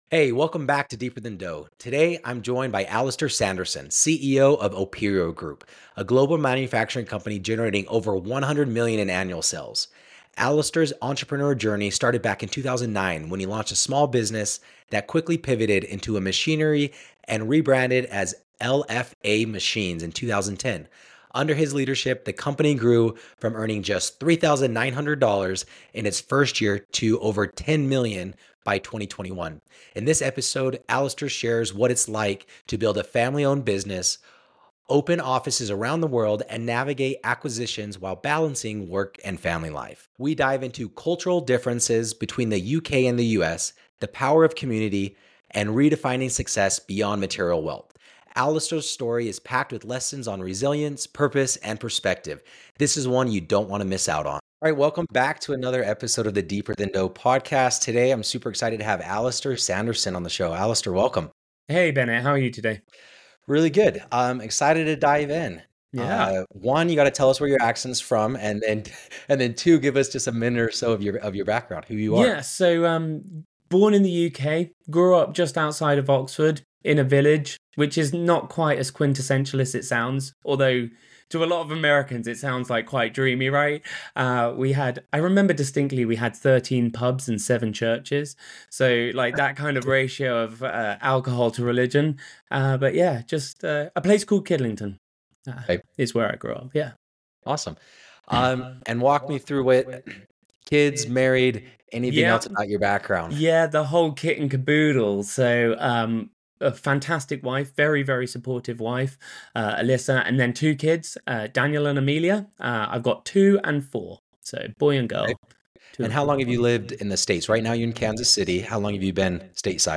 Tune in for an honest conversation about entrepreneurship, resilience, and finding purpose beyond profit.